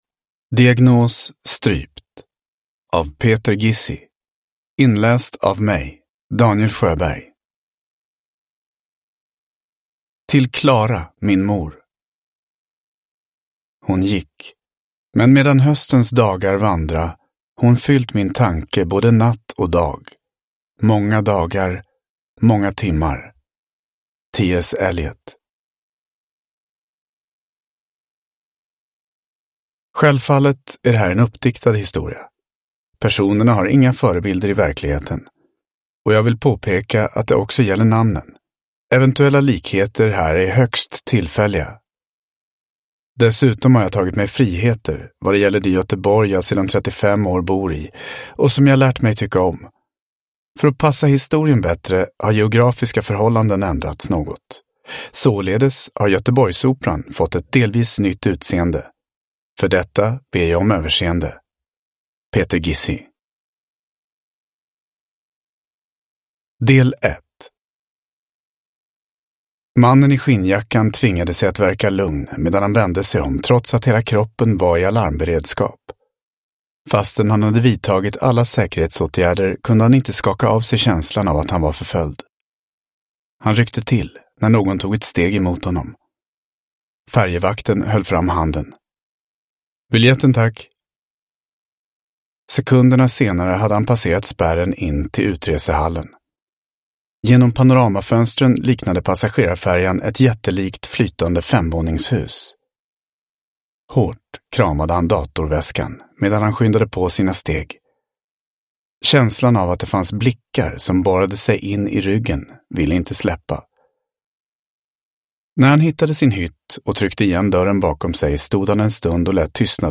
Diagnos: strypt – Ljudbok – Laddas ner